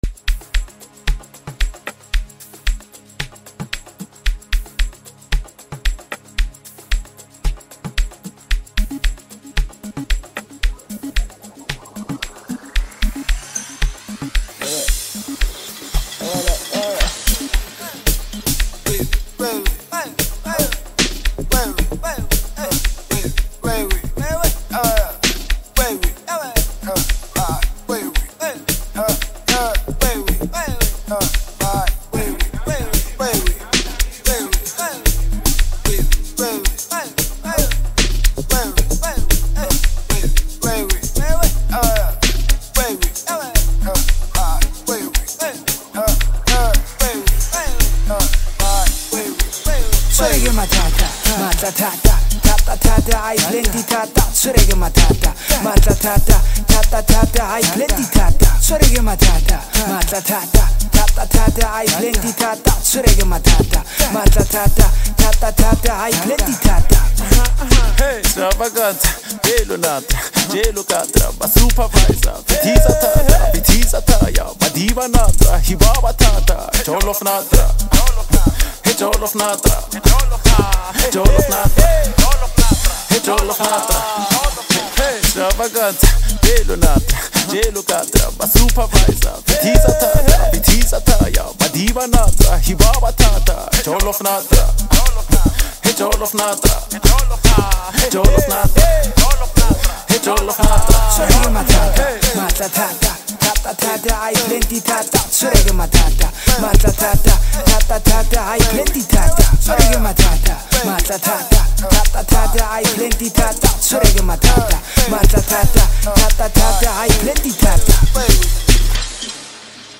Home » South African Music